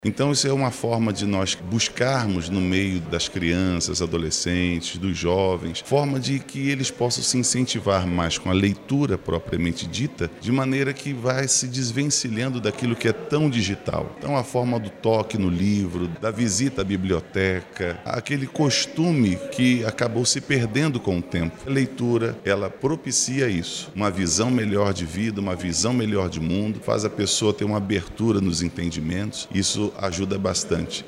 O autor da proposta destaca, ainda, que o PL também busca resgatar o contato com obras físicas e com ambientes voltados à literatura.